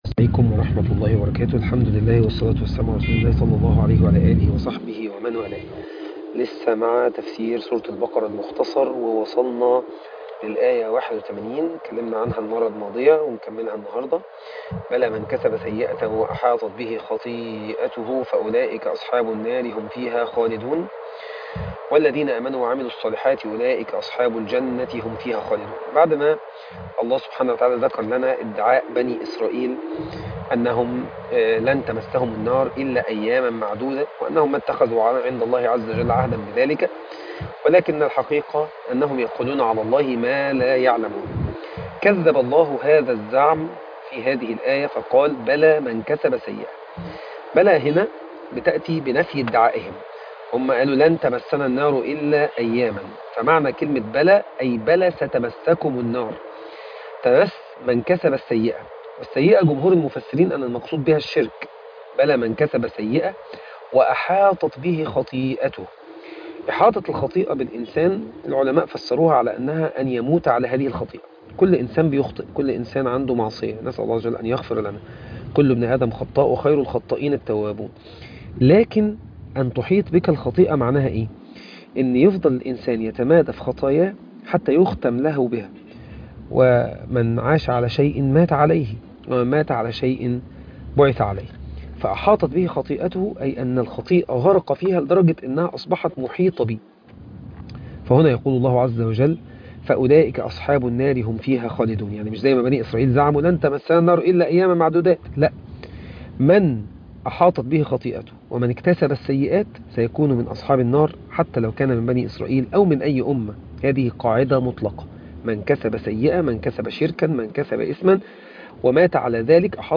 سورة البقرة 21 الآيات من81 إلي83 التلاوة